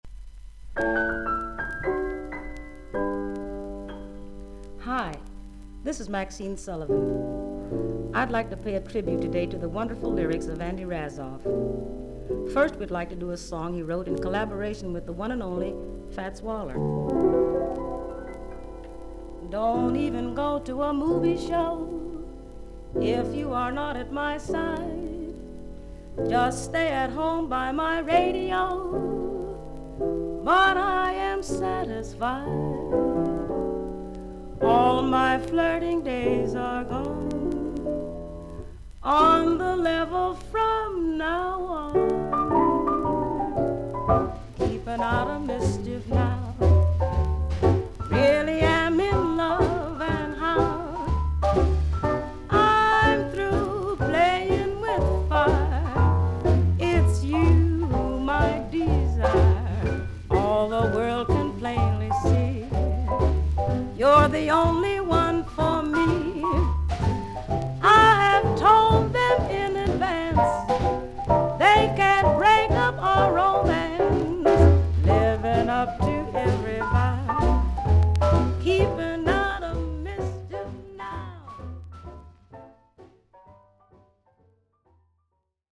少々軽いパチノイズの箇所あり。クリアな音です。
1930年代から活躍した新旧のセンス良いジャズ・フィーリングを持つシンガー。